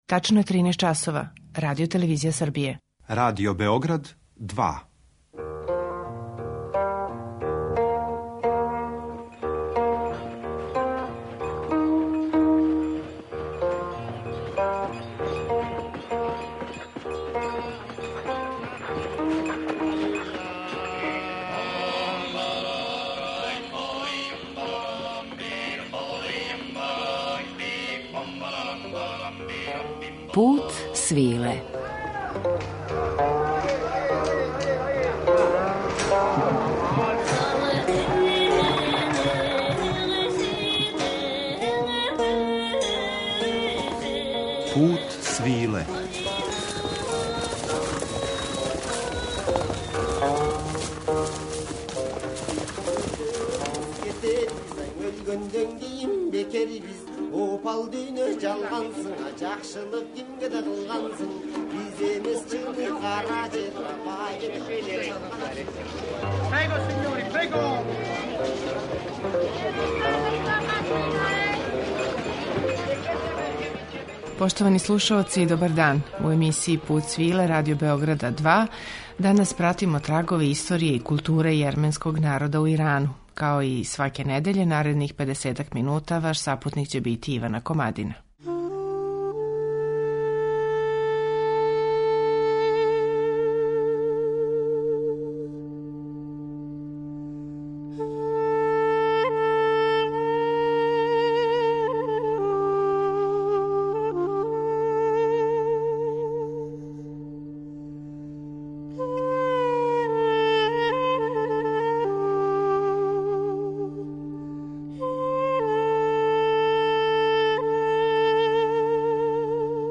Путевима којима су Јермени из Старе доспели до Нове Ђулфе, као и траговима које су на тим стазама оставили, посвећен је данашњи Пут свиле , који доноси и снимак концерта двојице великана јерменске и иранске музике, Ђивана Гаспаријана и Хосеина Ализадеа, који су 2003. године, са својим ансамблима, заједно наступили у предворју палате Ниаваран у Техерану.